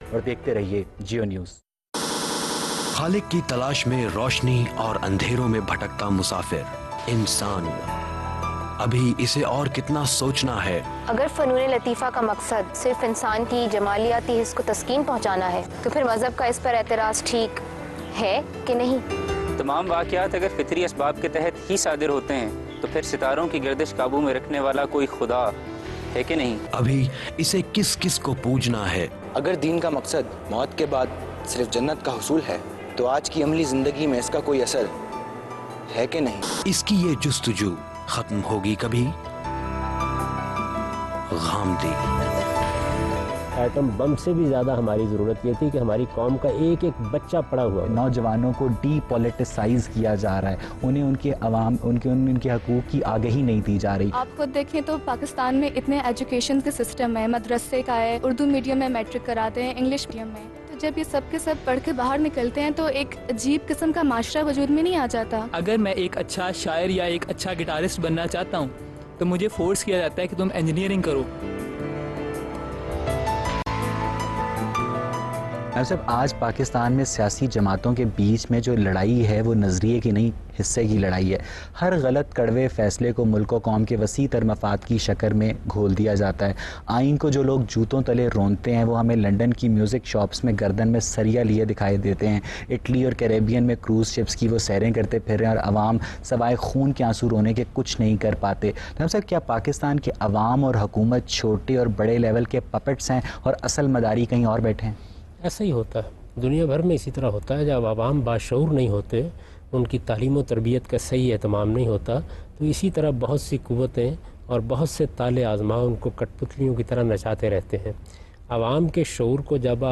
Questions and Answers on the topic “Can Pakistan change?” by today’s youth and satisfying answers by Javed Ahmad Ghamidi.